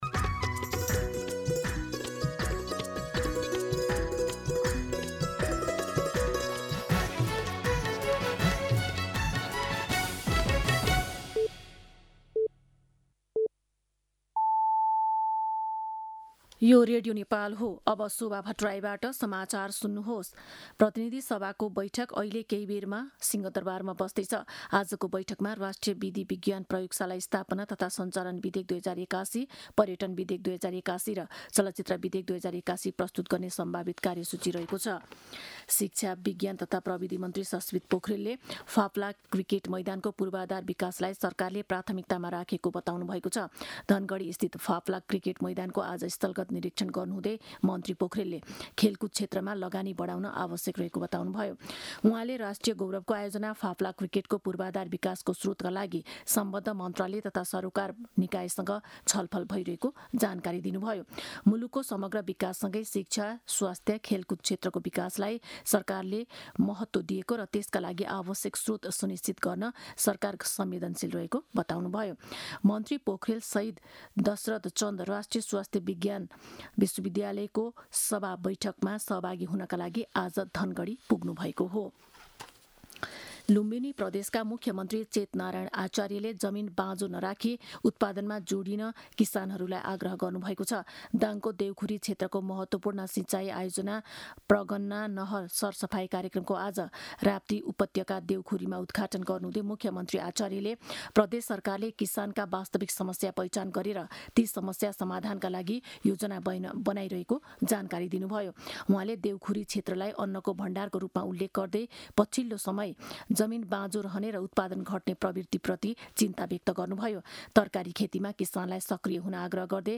दिउँसो १ बजेको नेपाली समाचार : २४ चैत , २०८२